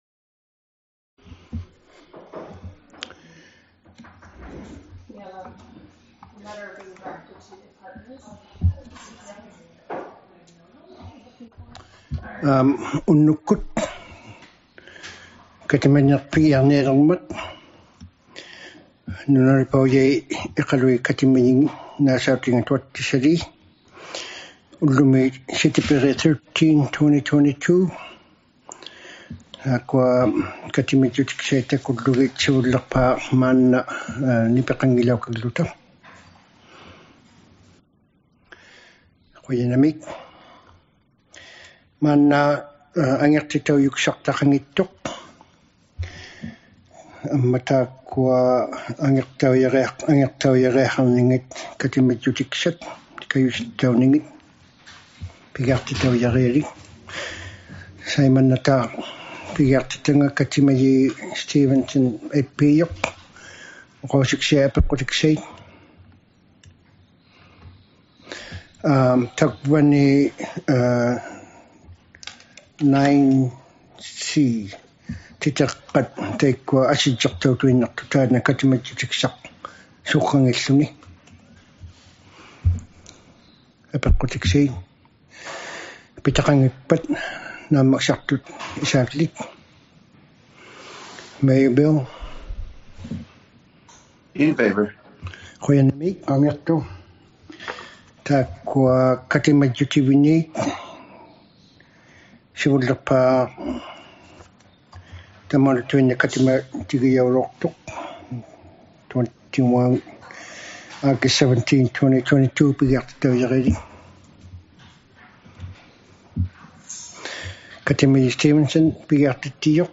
ᓄᓇᓕᐸᐅᔭᒃᑯᑦᑦ ᑲᑎᒪᔨᖕᒋᑦᑕ ᑲᑎᒪᓂᖕᒐᑦ #23 - City Council Meeting # 23 | City of Iqaluit